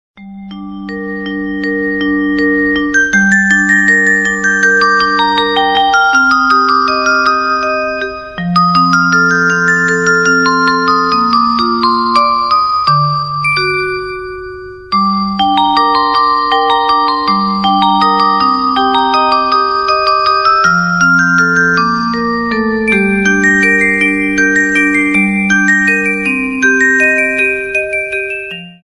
• Качество: 96, Stereo